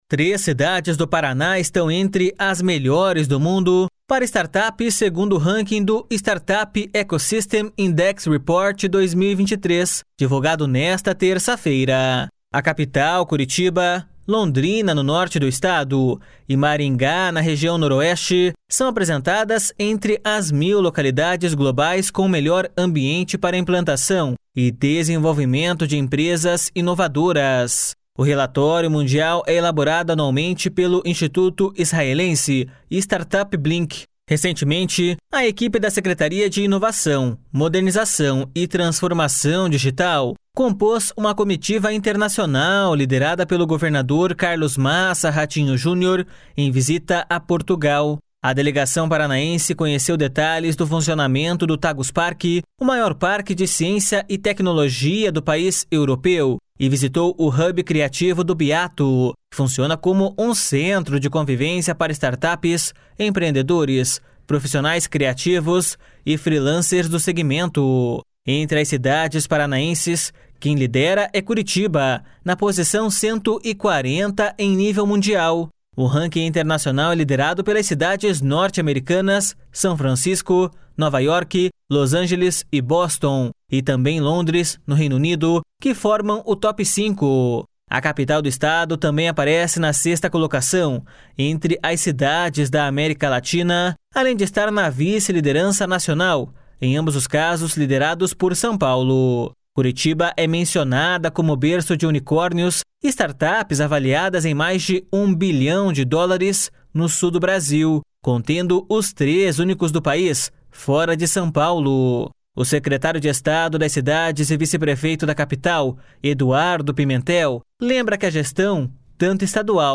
O secretário de Estado das Cidades e vice-prefeito da Capital, Eduardo Pimentel, lembra que a gestão, tanto estadual quanto municipal, busca incentivar o desenvolvimento de tecnologias para melhorar a vida do cidadão.// SONORA EDUARDO PIMENTEL.//